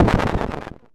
explosion-c.ogg